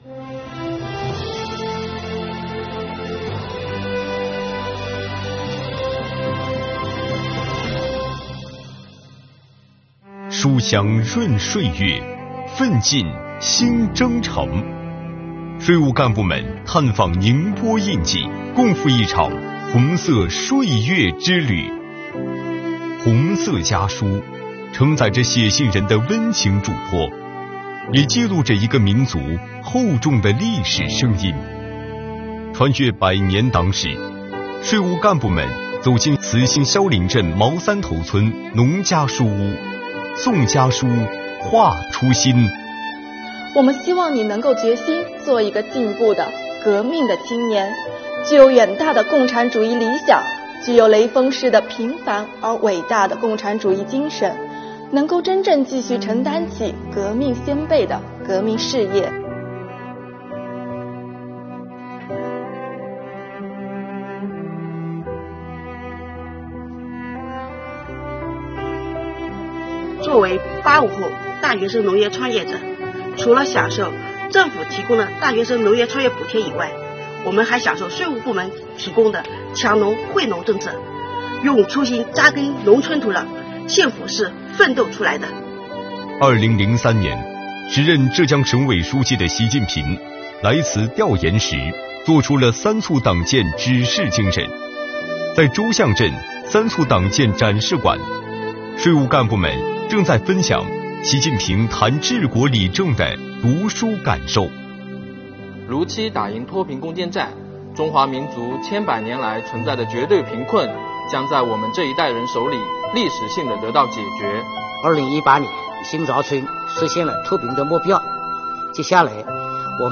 在新疆，国家税务总局塔城地区税务局开展迎“七一”“红心向党 兴税有我”主题活动，税务干部齐唱《没有共产党就没有新中国》，用最嘹亮的歌声、最真挚的情感表达对党和祖国的热爱与祝愿，凝心聚力扎实推进退税减税工作精准落地，以优异成绩迎接党的二十大胜利召开。